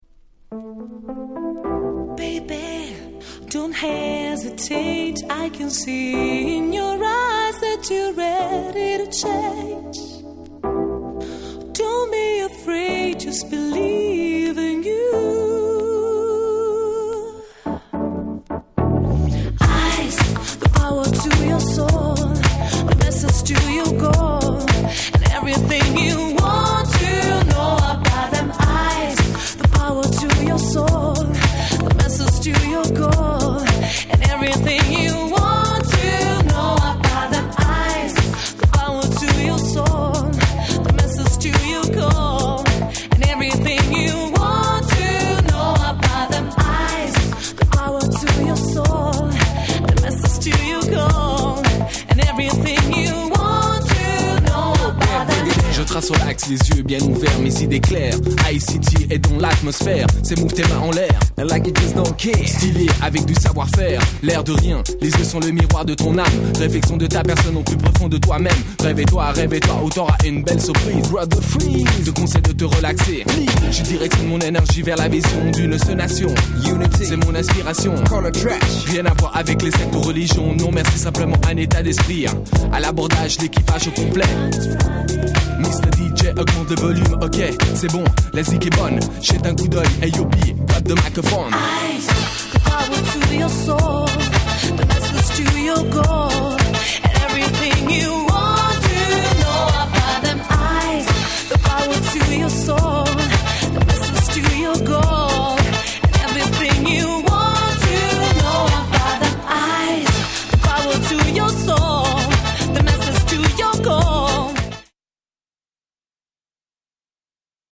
EU R&B Classic !!
未だに人気の衰えないキャッチー系EU R&B Classics !!
Acid Jazzタイプの爽やかな